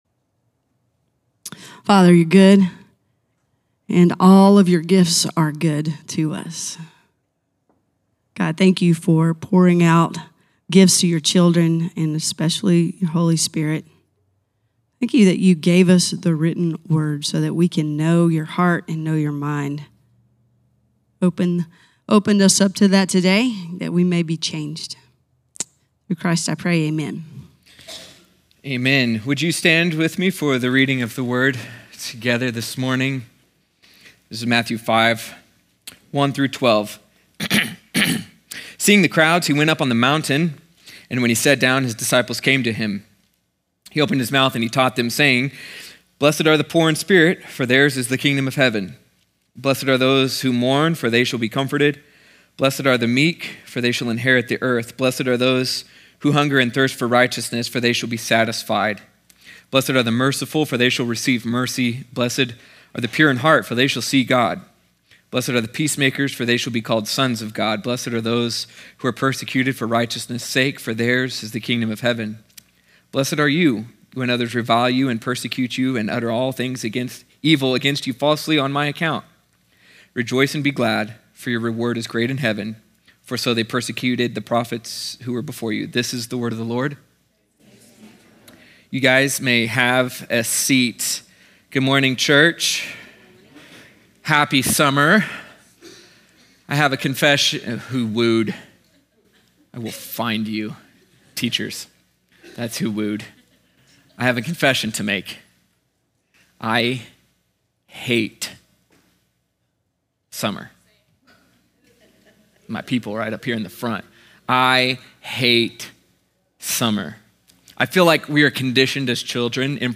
sermon audio 0608.mp3